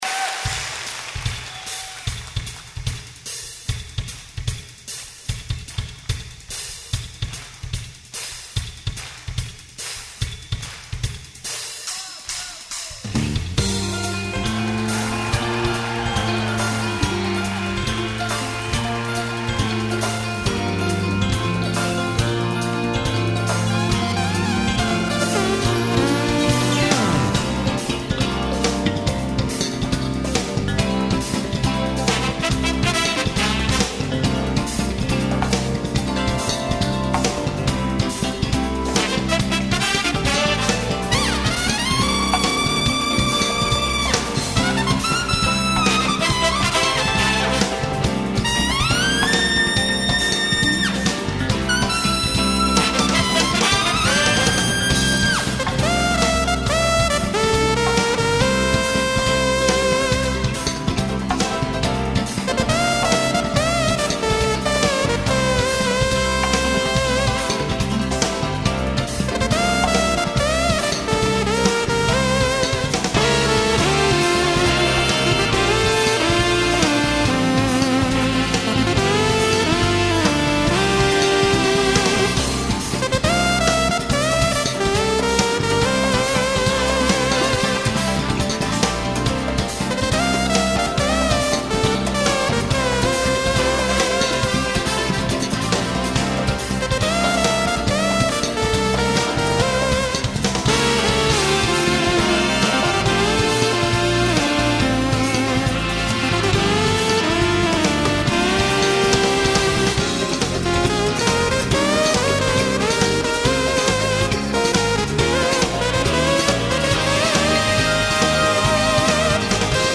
후반부 브라스 편곡 예술입니다....